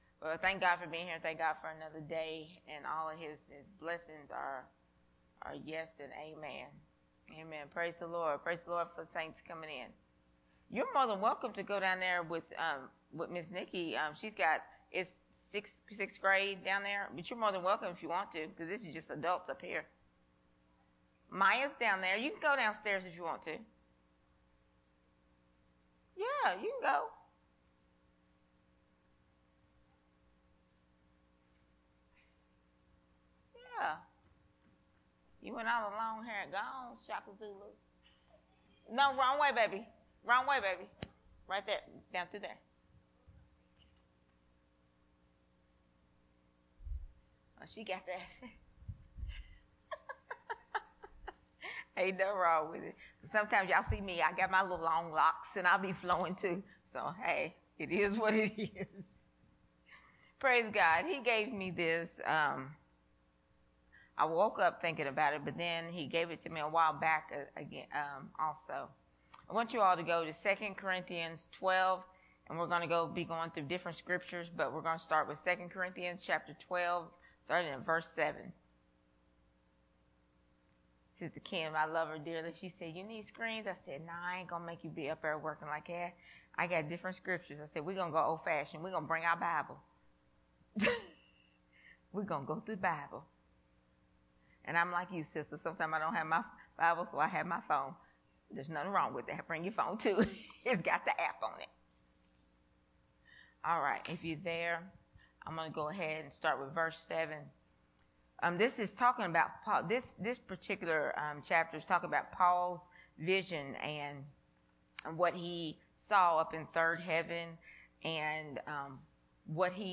a family Training Hour teaching
recorded at Unity Worship Center on July 6th, 2022.